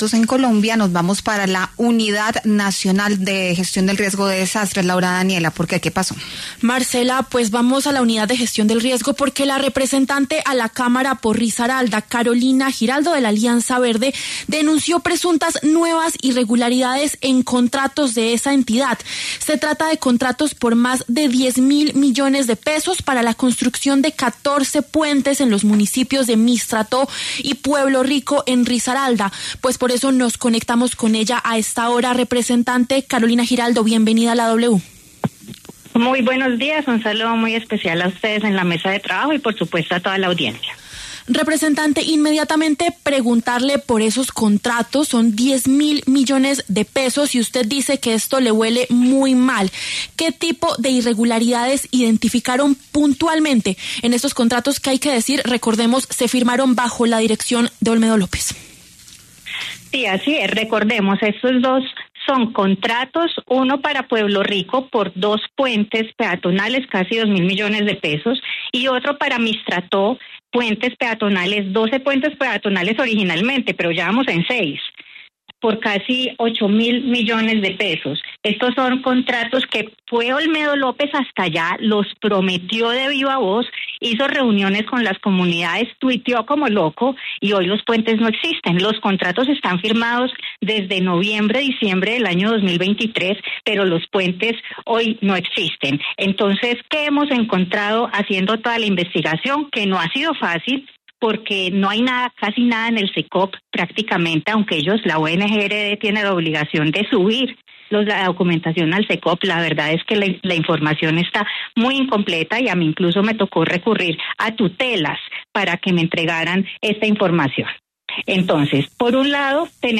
La representante Carolina Giraldo, de Alianza Verde, y el director de la UNGRD, Carlos Carrillo, pasaron por los micrófonos de La W.